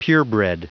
Prononciation du mot purebred en anglais (fichier audio)
Prononciation du mot : purebred